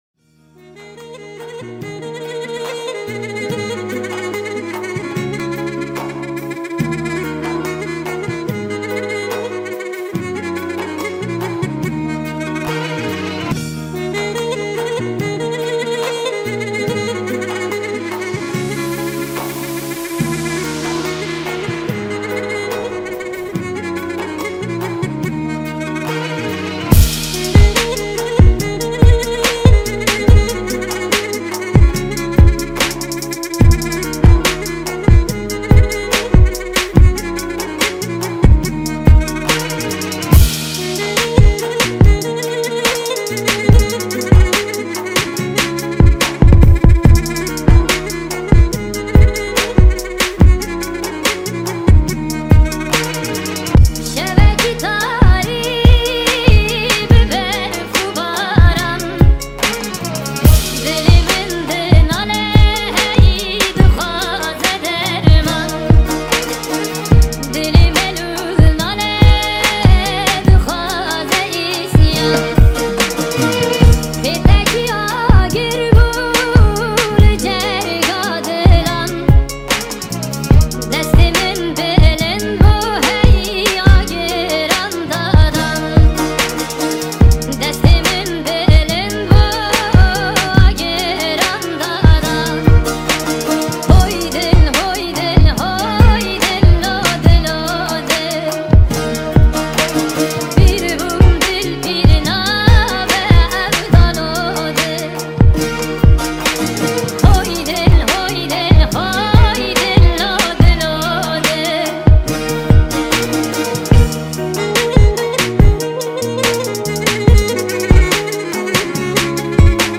ریمیکس جدید